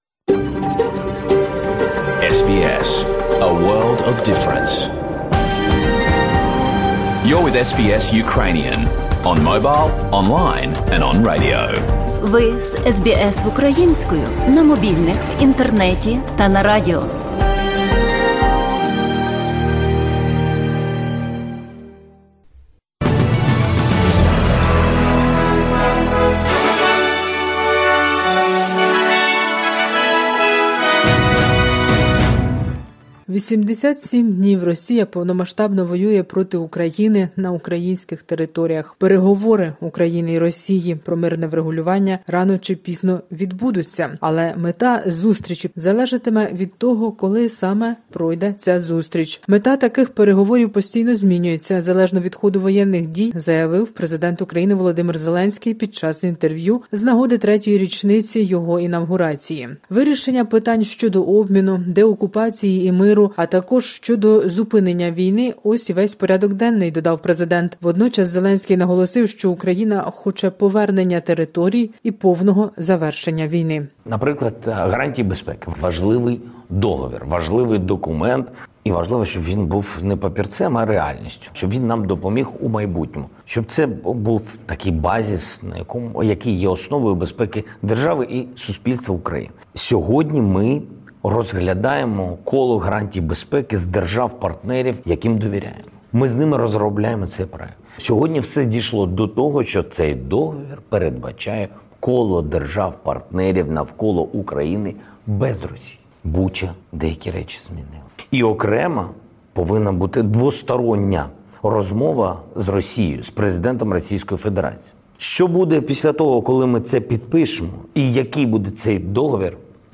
Добірка новин із воюючої України спеціально для SBS Ukrainian.